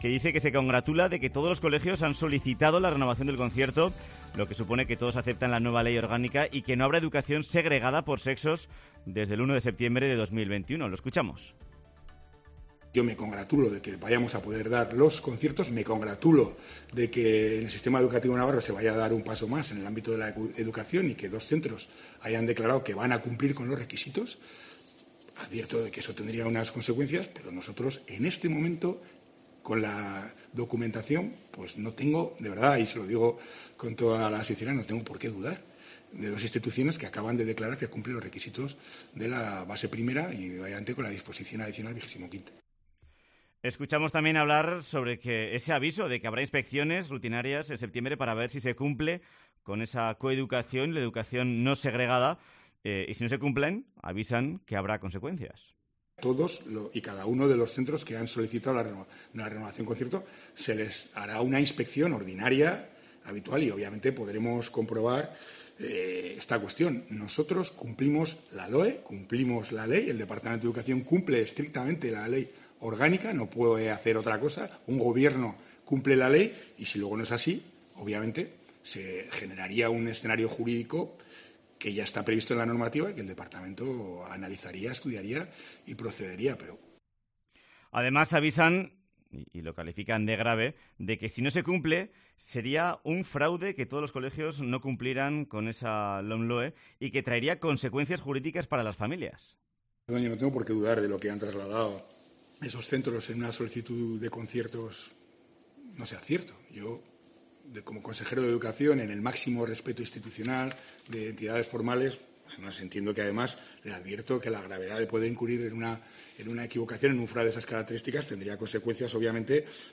Entrevista con Javier Esparza, presidente UPN